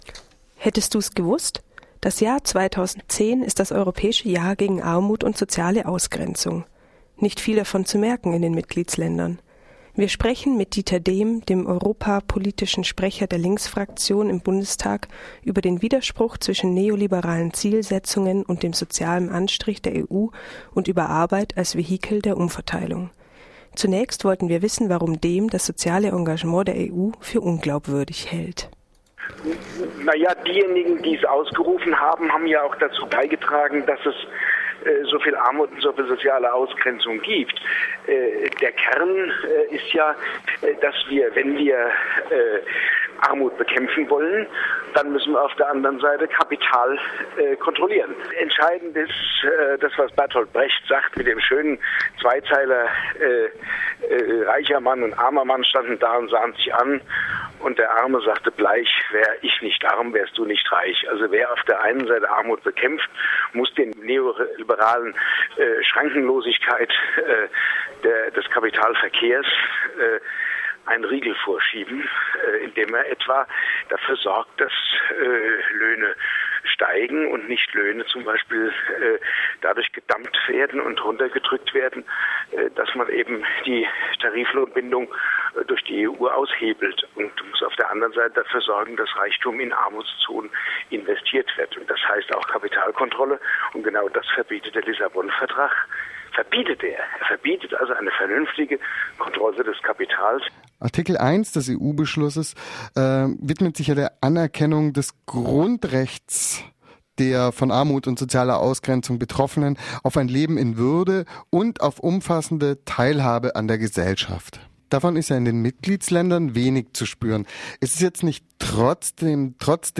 Interview mit Diether Dehm zum "Europäischen Jahr 2010"